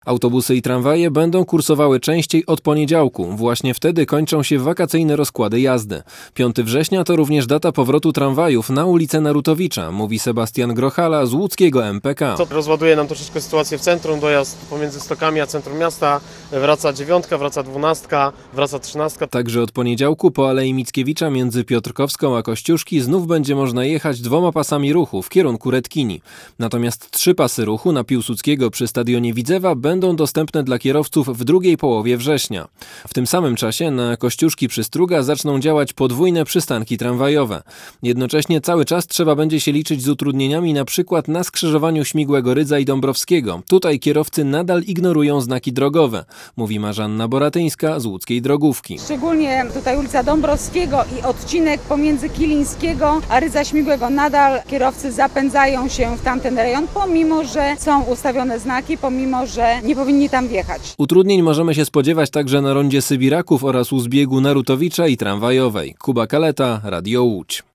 Posłuchaj materiału naszego reportera i dowiedz się więcej: